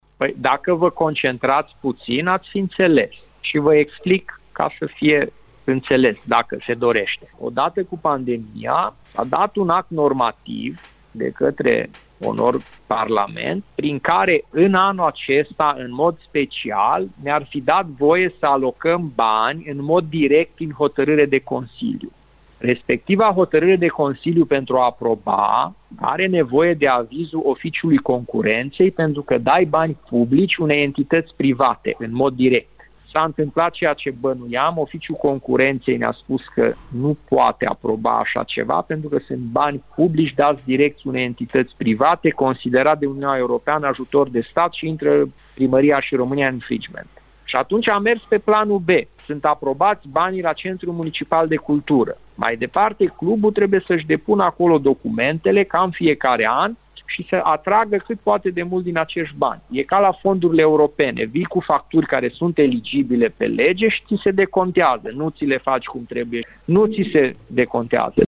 Întrebat de un ascultător Radio Timișoara de ce nu s-a aprobat finanțarea, primarul ales al Aradului, Călin Bibarț, i-a cerut… să se concentreze mai bine: